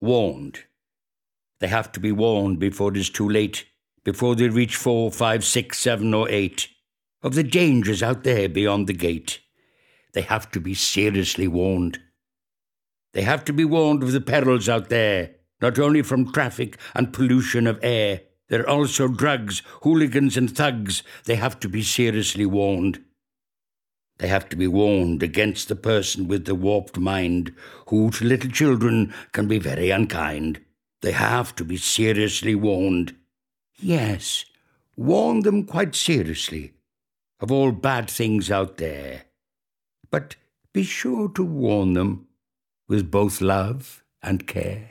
Click here to play poem read by Victor Spinetti